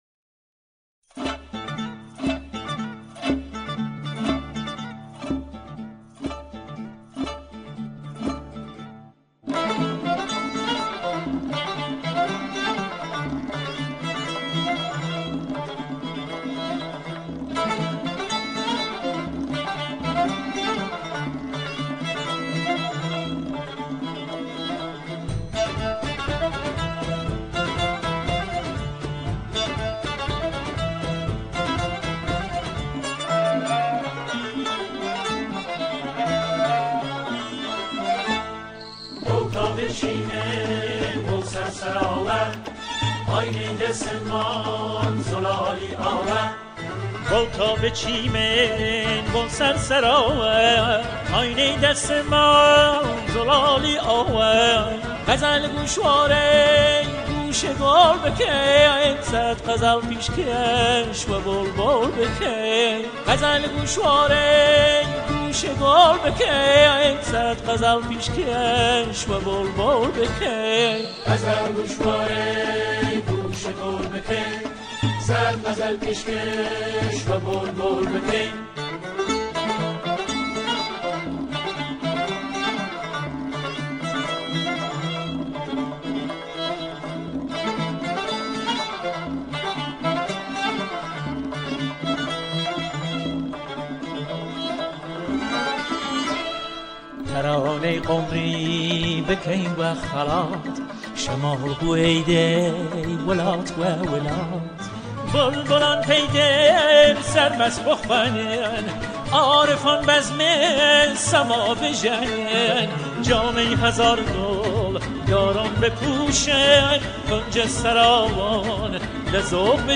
همراهی گروه همخوان